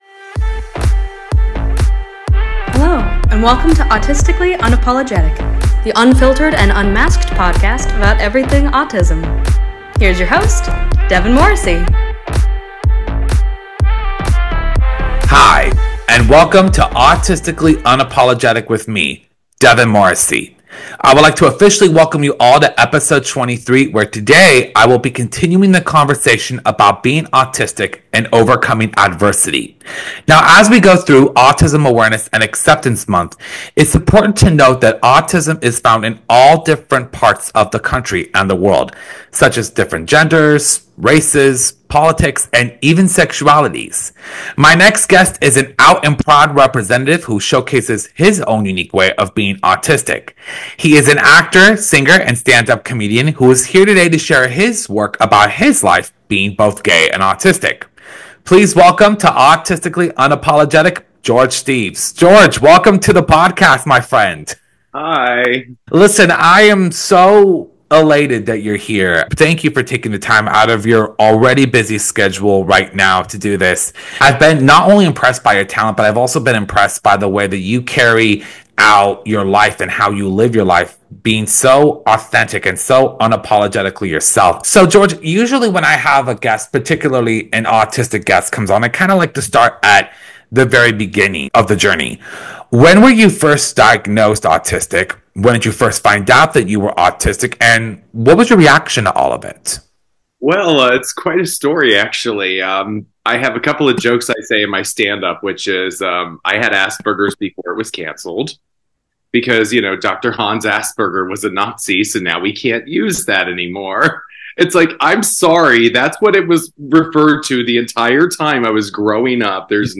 insightful conversation about chasing and pursuing the dreams and goals that were meant to be put in your direction.